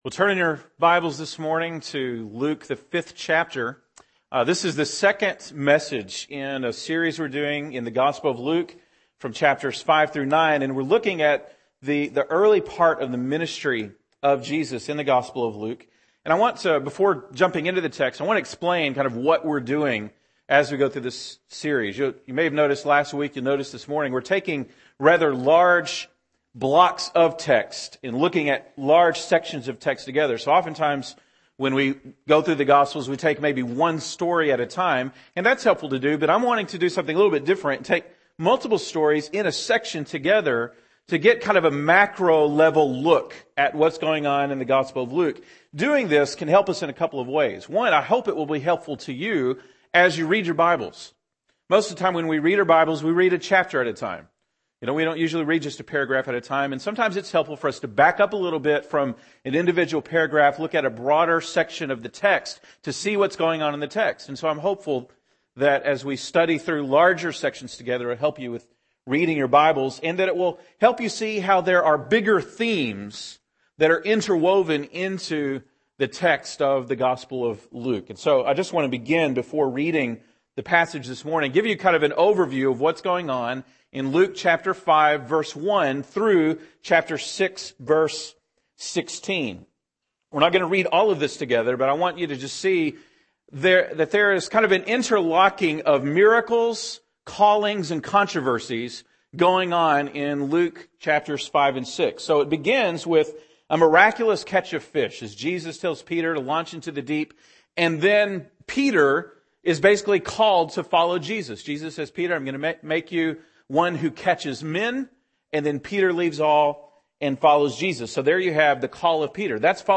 February 5, 2012 (Sunday Morning)